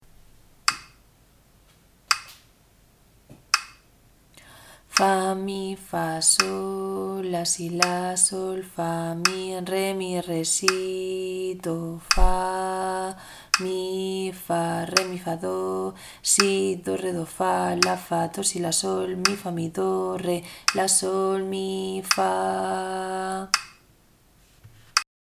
ritmo_3_5.mp3